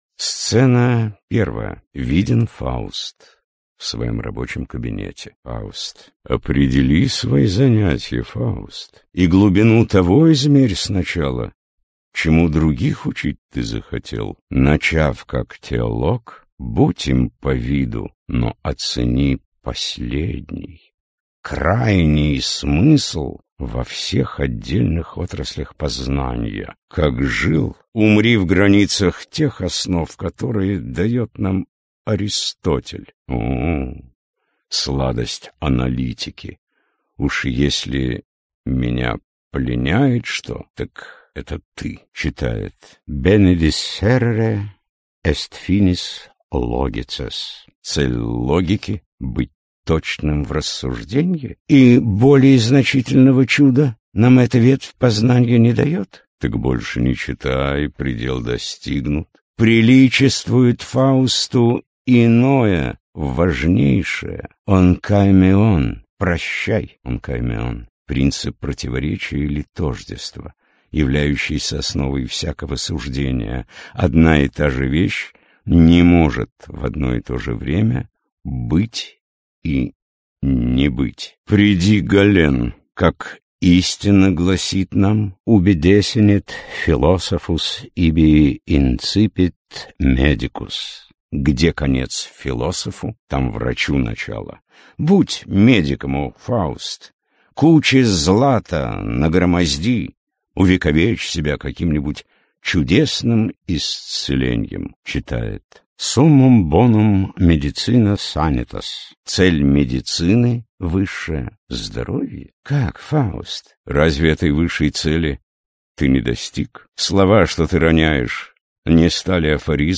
Аудиокнига Трагическая история доктора Фауста | Библиотека аудиокниг